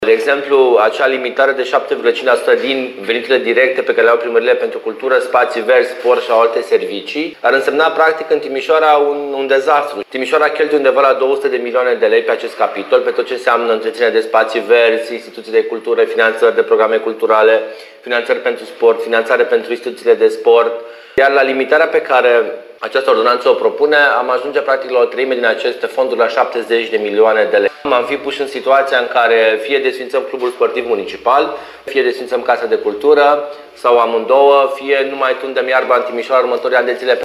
Ordonanța austerității, pe care o pregătește Guvernul, ar crea un adevărat dezastru dacă ar apărea în forma draftului care circulă deja în mediul online, spune Ruben Lațcău, viceprimarul USR al Timișoarei.